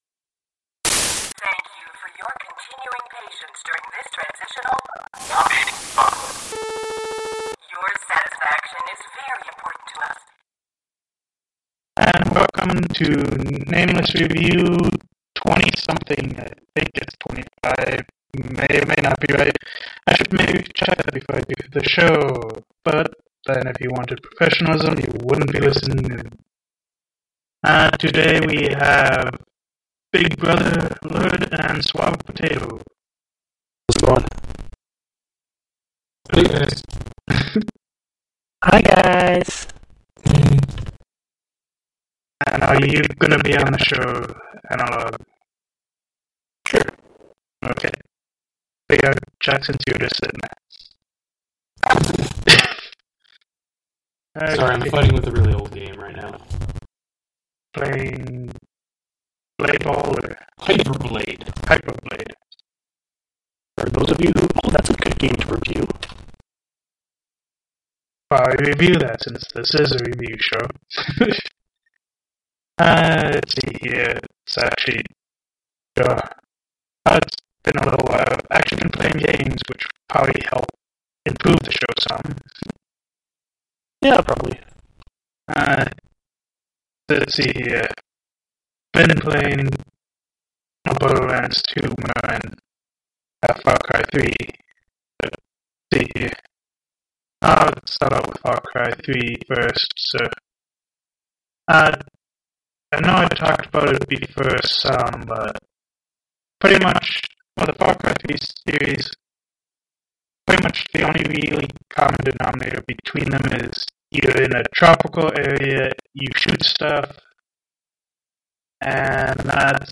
Today we review Far Cry 3, Don’t Starve, and others We’re aware of an audio issue with the recording.